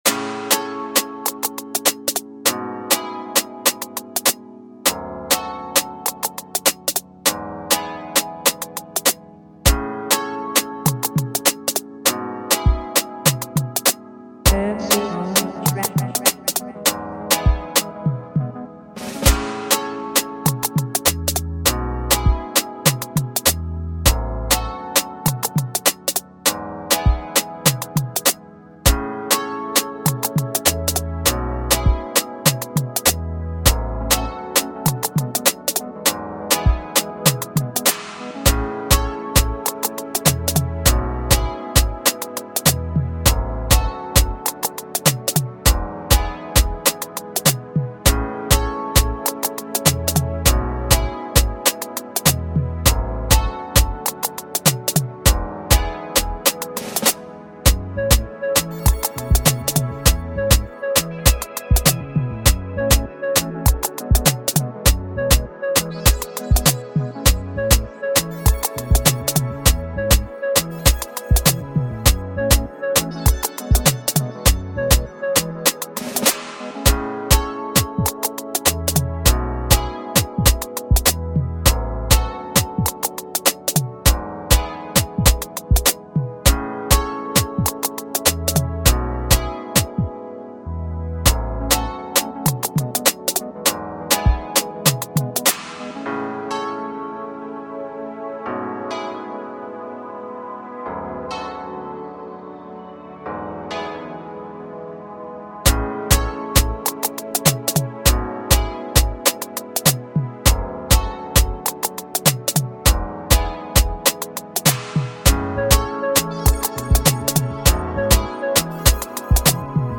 free beat Instrumental